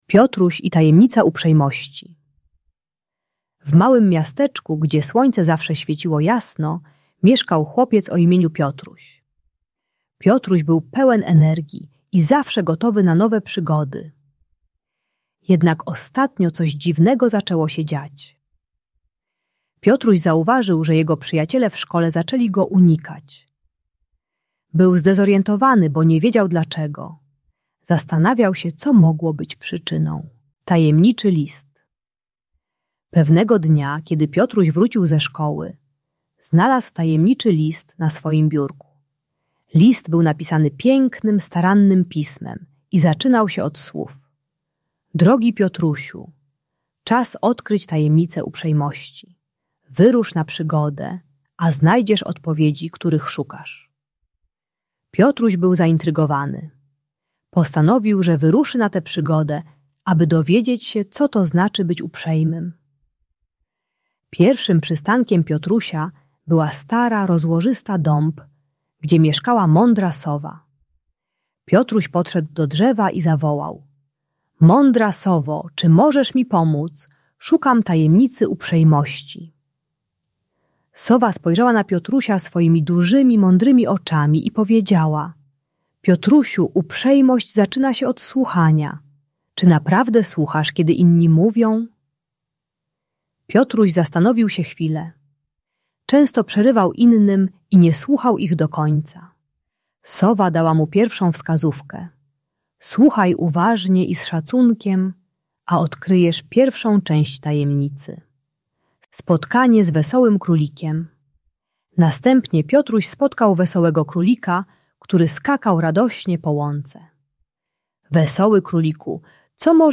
Uczy trzech zasad uprzejmości: uważnego słuchania, pomagania innym i szanowania ich uczuć. Audiobajka o budowaniu przyjaźni w szkole.